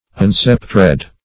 Search Result for " unsceptred" : The Collaborative International Dictionary of English v.0.48: Unsceptered \Un*scep"tered\, Unsceptred \Un*scep"tred\, a. 1.